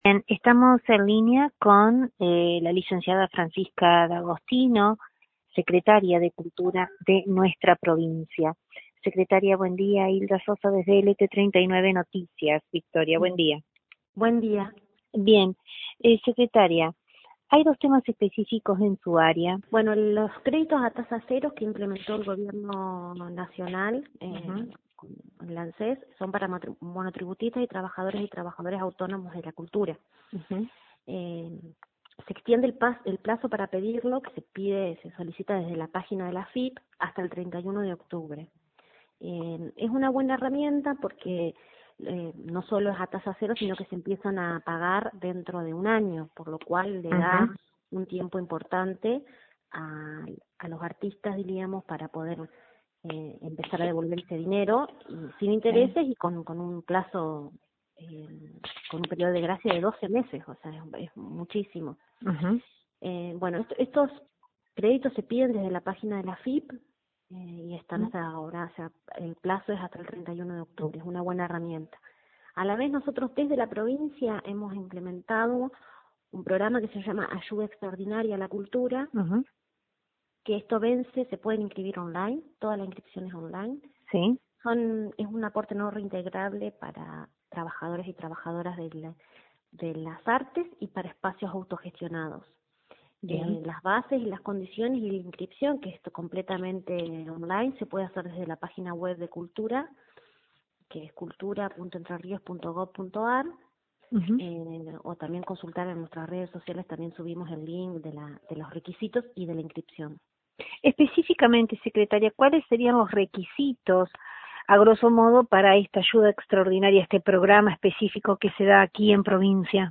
Sobre las AYUDAS ECONÓMICAS al sector cultural entrerriano, hablamos con la Secretaria de Cultura de la provincia
Desde LT39 NOTICIAS, entrevistamos a la Secretaria de Cultura de Entre Ríos, Licenciada Francisca D´Agostino; quien mas allá de detalllar lo escrito con antelación, expresó que desde su cartera, se trabaja en un lineamiento, enfocados en el futuro.